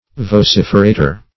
\Vo*cif"er*a`tor\